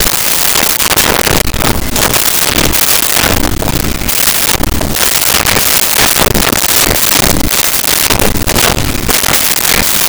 Glasses Cup Movement
Glasses Cup Movement.wav